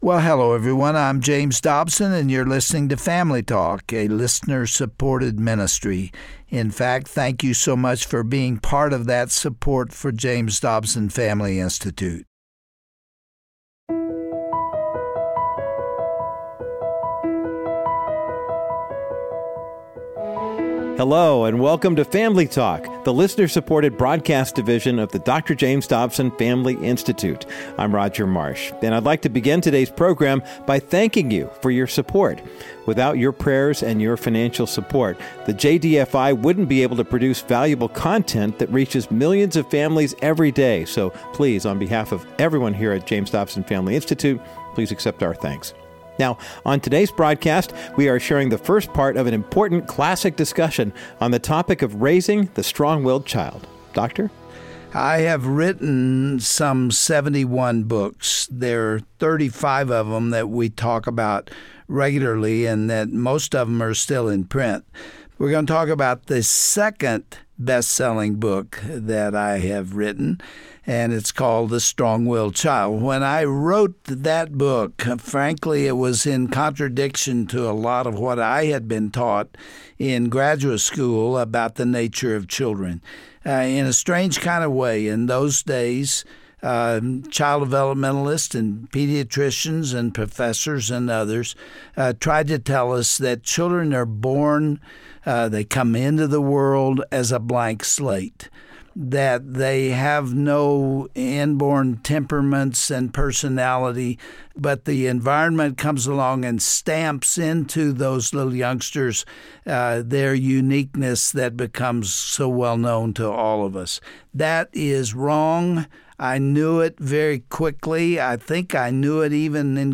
On today's edition of Family Talk, Dr. Dobson, author of The New Strong-Willed Child, talks to a panel of three formerly exhausted mothers who struggled with their strong-willed children. While they each administered a healthy dose of love, discipline, and prayer, the Lord rewarded their patience and tears with well-adjusted adult children.